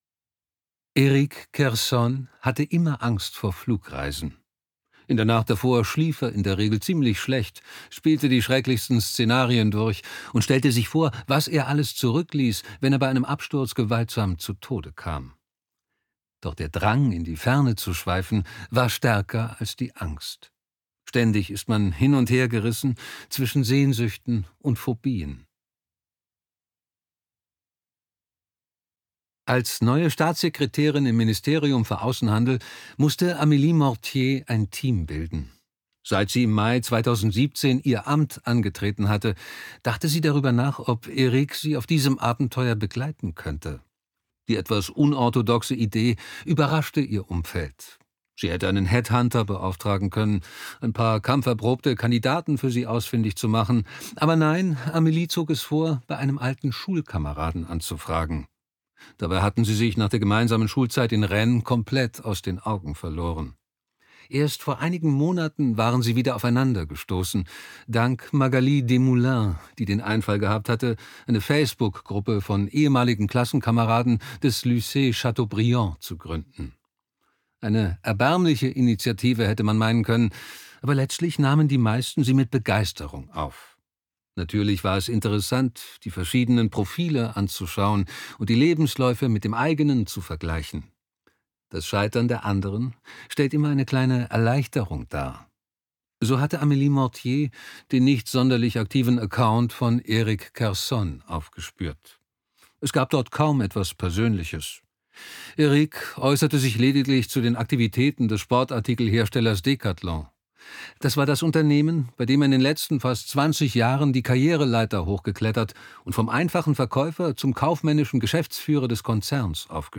Das glückliche Leben - David Foenkinos | argon hörbuch
Gekürzt Autorisierte, d.h. von Autor:innen und / oder Verlagen freigegebene, bearbeitete Fassung.